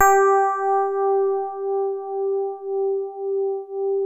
FINE HARD G3.wav